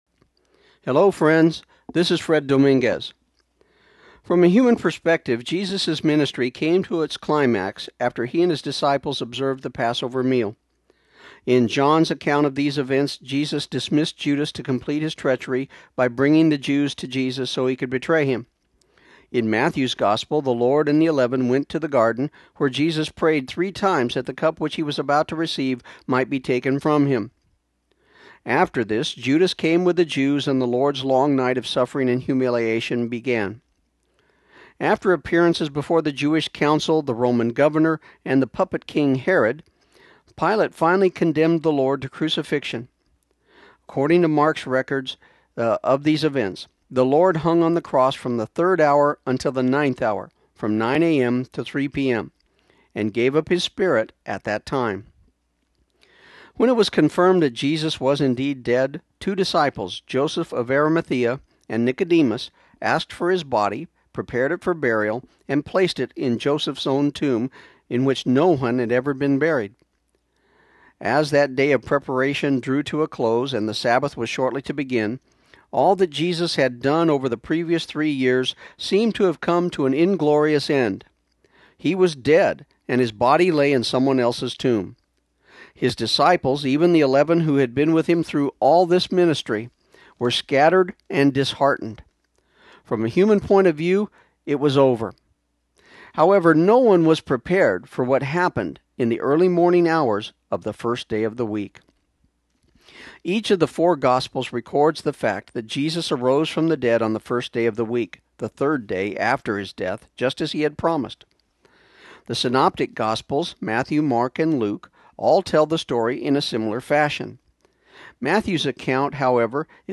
This program aired on KIUN 1400 AM in Pecos, TX on April 6, 2015